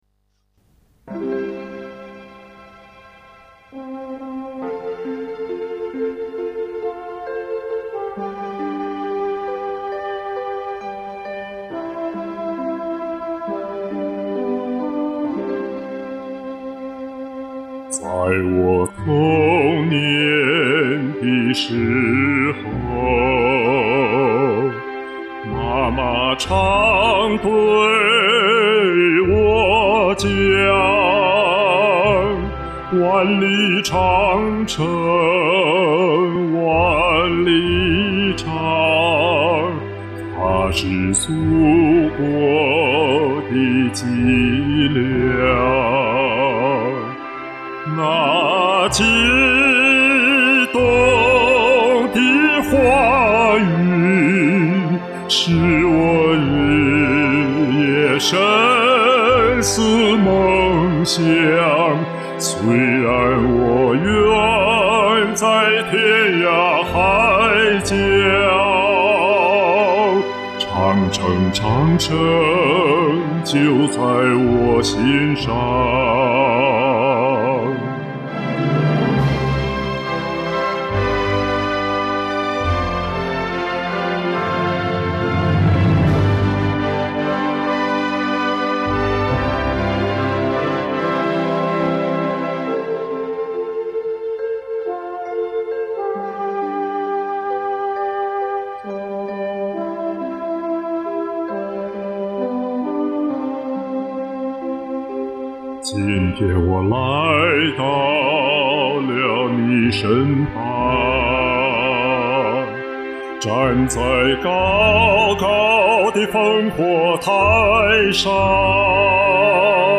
升两个key再唱，希望能有所进步。
厚，大气深情，非常好听！
细腻有感情！结尾激情有力量！